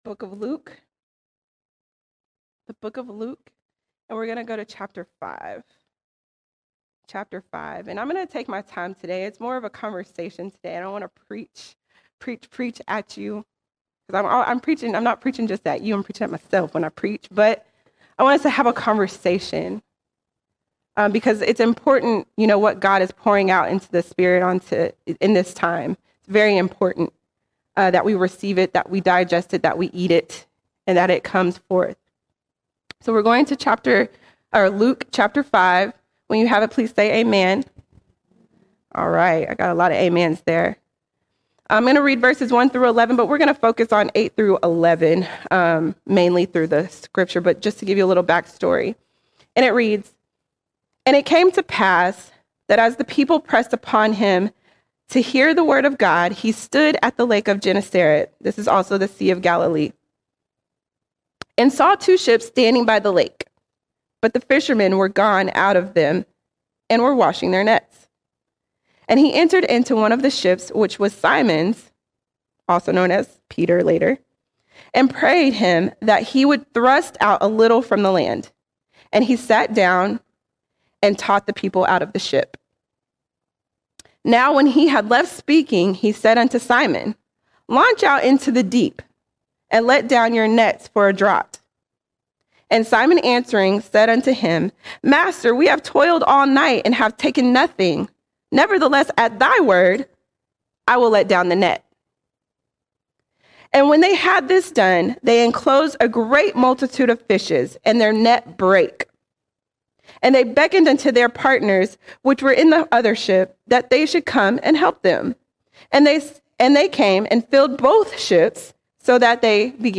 24 March 2025 Series: Sunday Sermons All Sermons A Silent Yes A Silent Yes The call to follow Jesus deserves more than a Silent Yes.